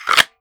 Kydex Holster 003.wav